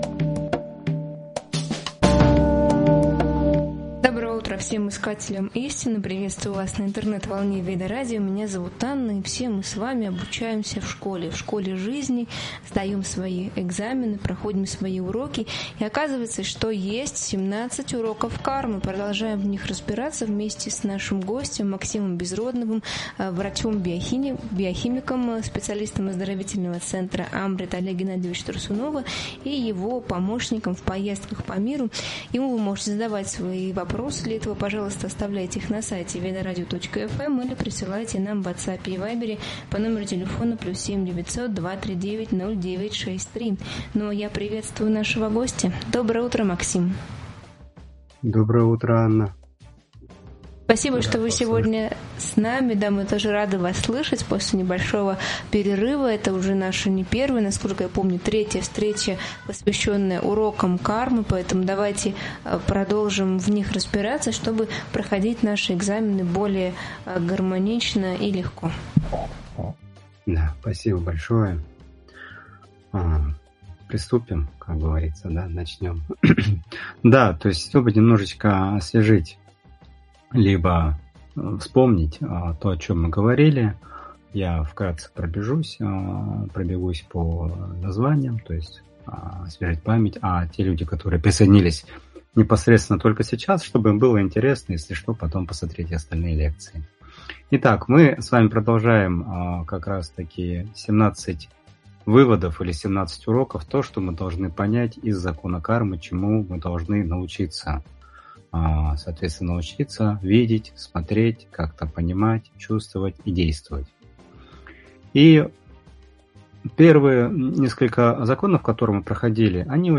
В эфире раскрываются уроки кармы и их роль в духовной жизни. Обсуждается, как осознанные действия, регулярная практика и служение Богу помогают нейтрализовать последствия прошлых поступков.